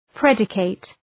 Προφορά
{‘predıkıt}